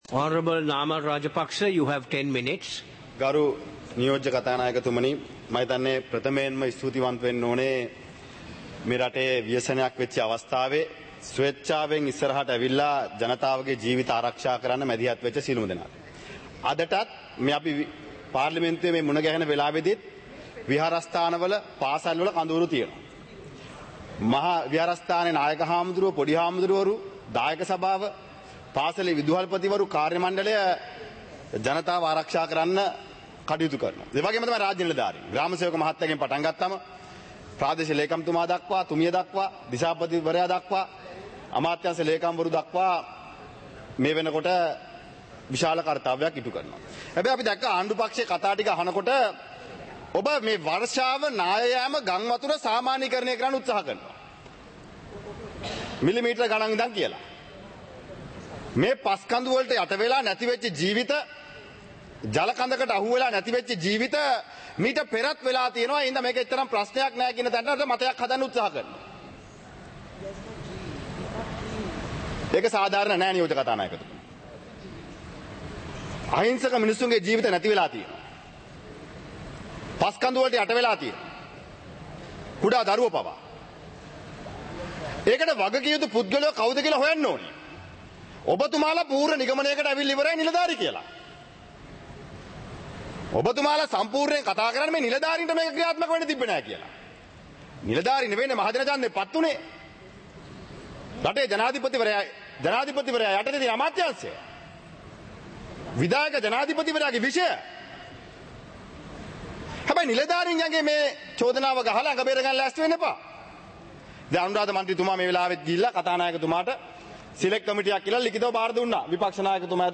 පාර්ලිමේන්තුව සජීවීව - පටිගත කළ